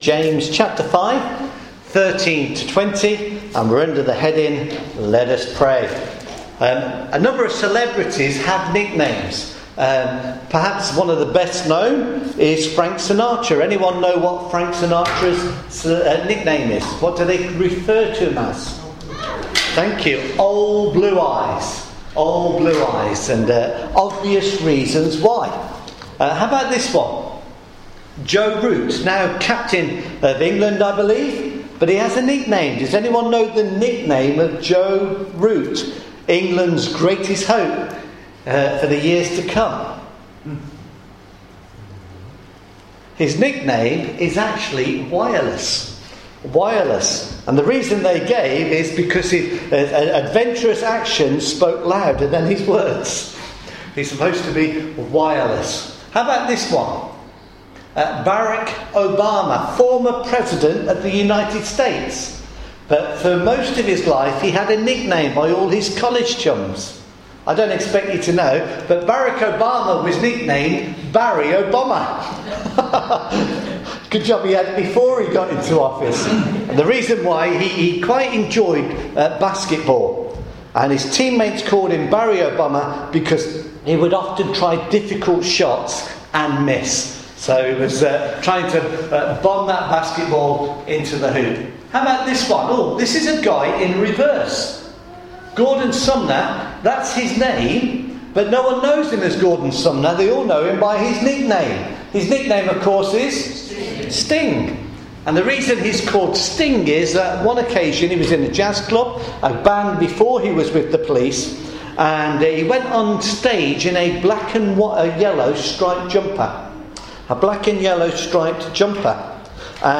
James chapter 5 verses 7-12 – sermon